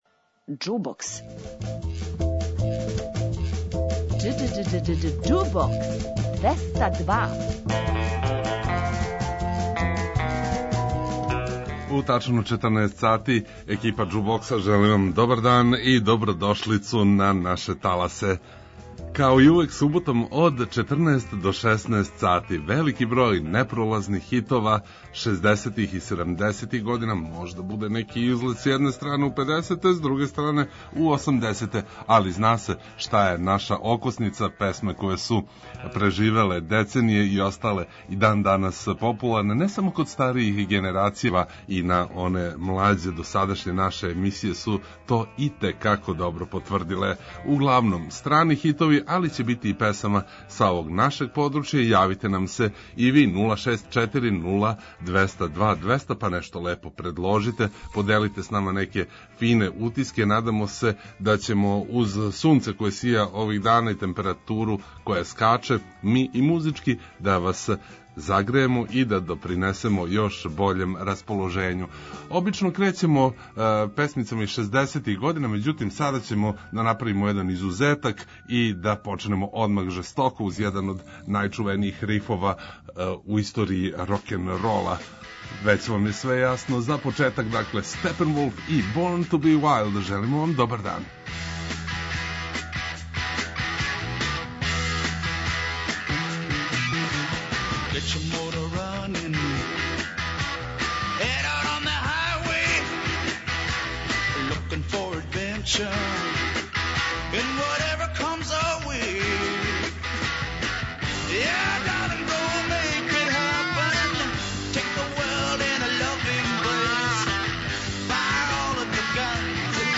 Обиље хитова шездесетих и седамдесетих година, што са стране, што са овдашње сцене, спремили смо за вас и овог поподнева.